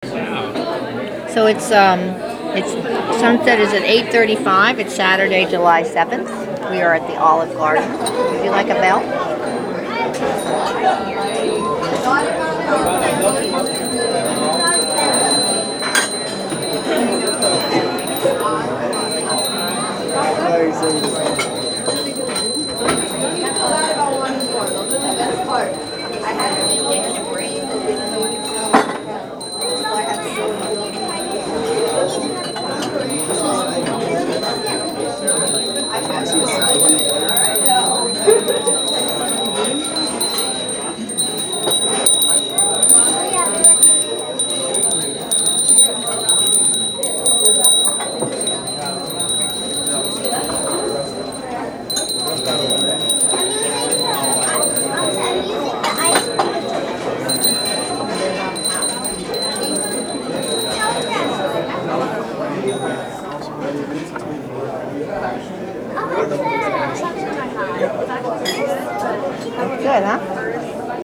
Sunset 8:35 PM. Olive Garden, Stonestown Mall. San Francisco
That place was so busy nobody really noticed the little bells among the chatter and clatter
(listen) bouncing off the newly renovated walls.